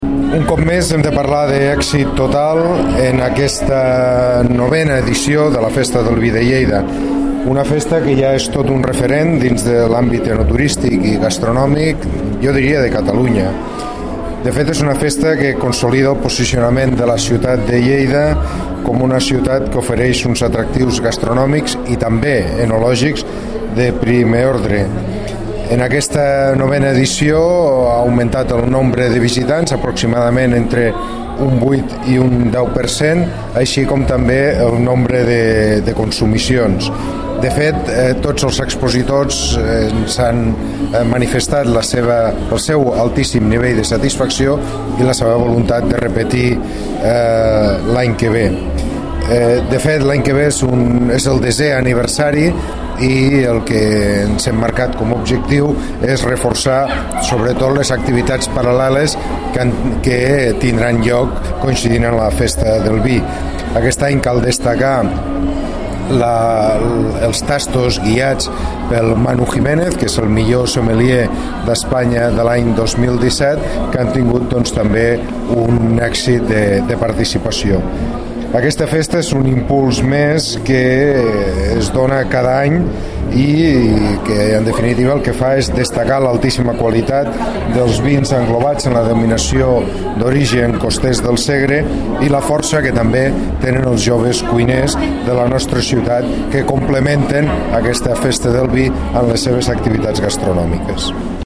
Tall de veu R. Peris.